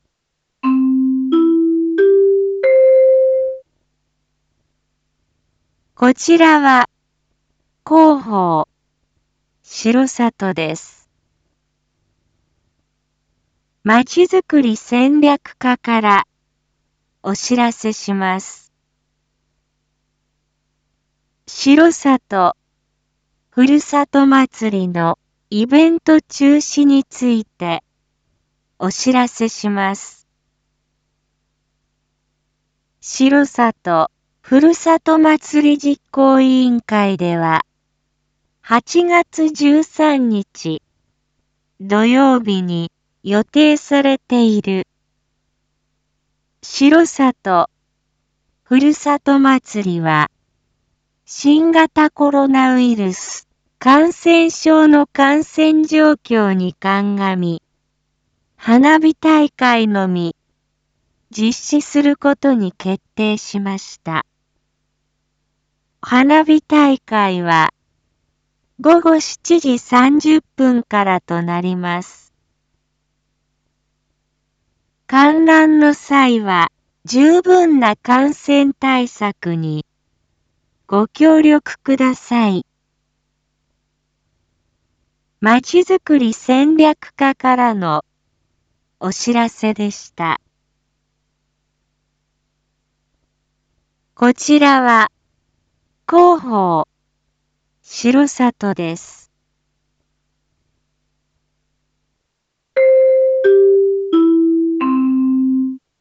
Back Home 一般放送情報 音声放送 再生 一般放送情報 登録日時：2022-08-06 19:01:47 タイトル：R4.8.6 19時放送分 インフォメーション：こちらは広報しろさとです。